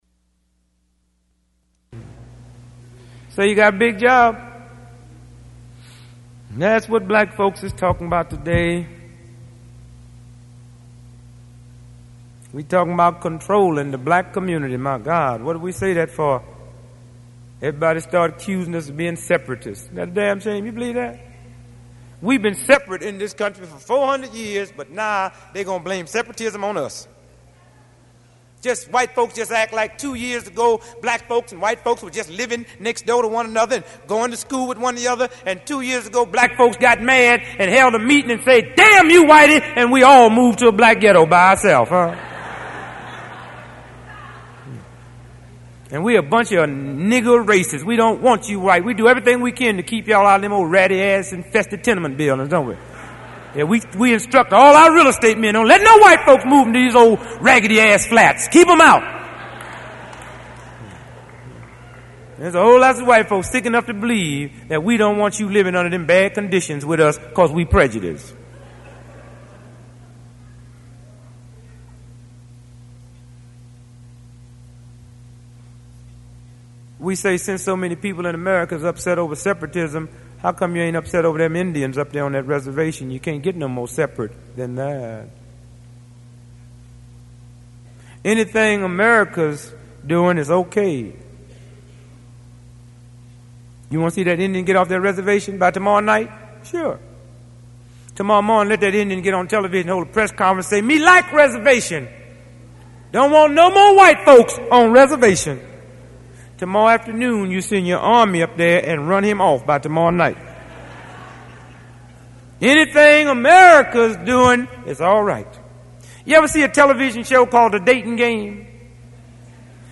This is a speech that the political comedian Dick Gregory gave at San Francisco State in 1971.